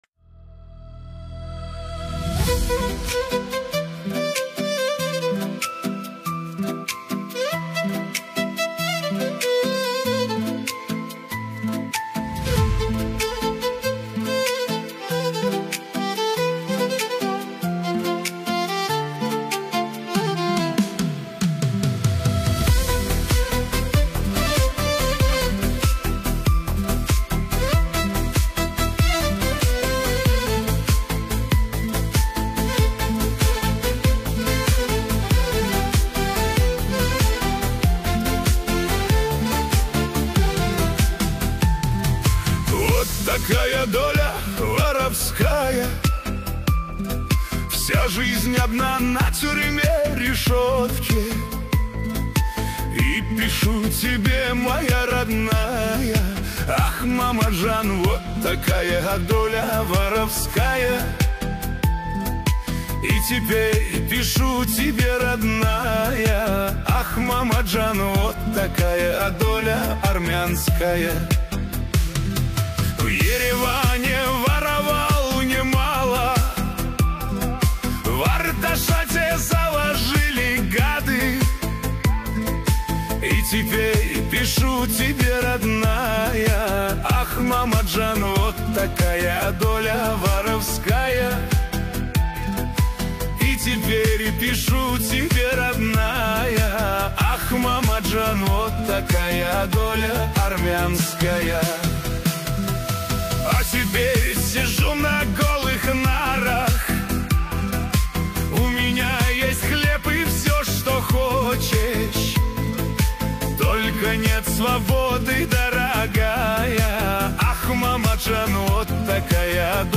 с новой обработкой